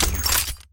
EquipOn.wav